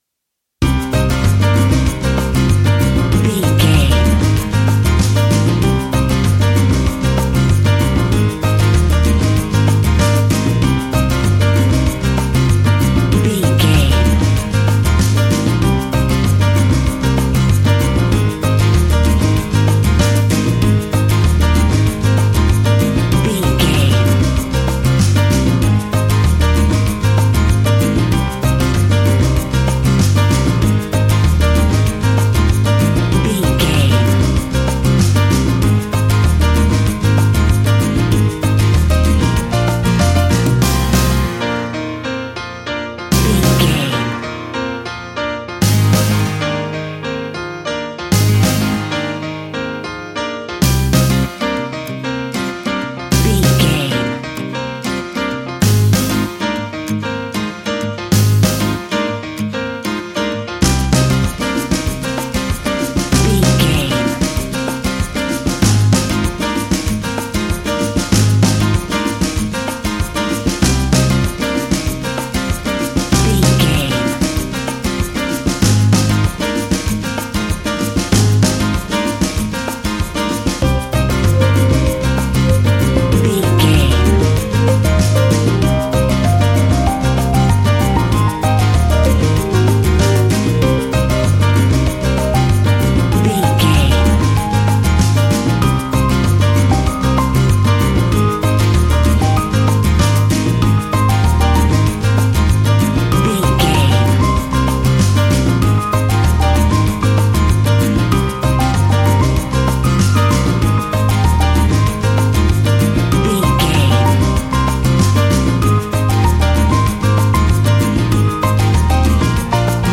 An exotic and colorful piece of Espanic and Latin music.
Aeolian/Minor
maracas
percussion spanish guitar
latin guitar